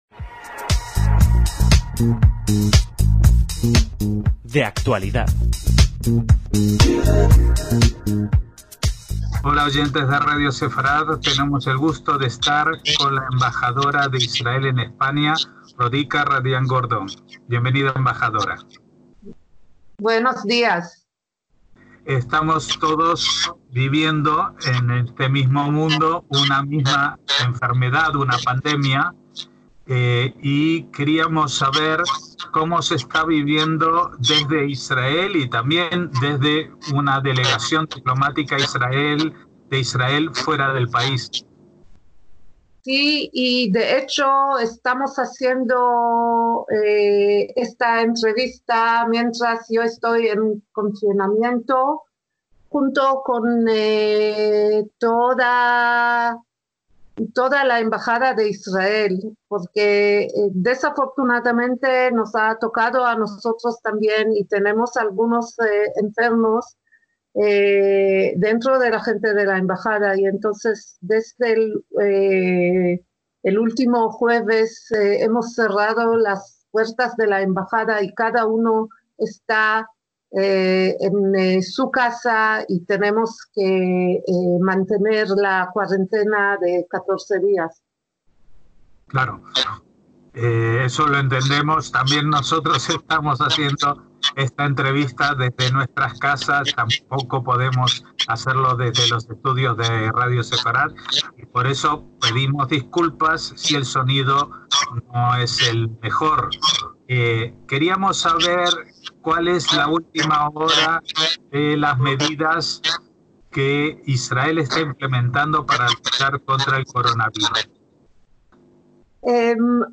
DE ACTUALIDAD - Tuvimos la oportunidad de hablar en exclusiva con la embajadora de Israel en España desde su confinamiento ya que, además de las pautas generales para toda la población, también la delegación diplomática se ha visto afectada. Además de contarnos sobre las medidas tomadas en las últimas horas en Israel, aclaramos algunos de los temas vinculados a ello que ha protagonizado titulares en la prensa de aquí y de allí, como el cierre de escuelas rabínicas o la famosa vacuna israelí.